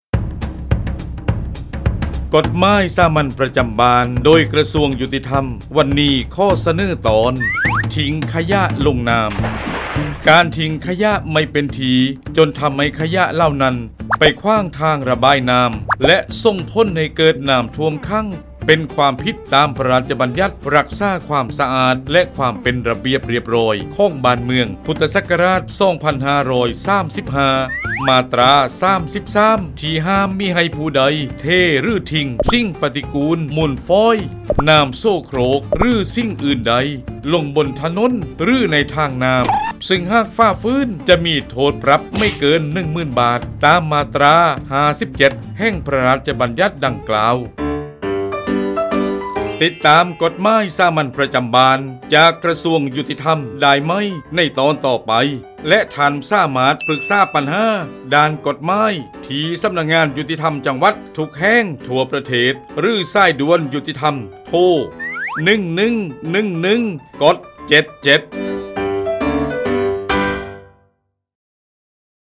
กฎหมายสามัญประจำบ้าน ฉบับภาษาท้องถิ่น ภาคใต้ ตอนทิ้งขยะลงน้ำ
ลักษณะของสื่อ :   คลิปเสียง, บรรยาย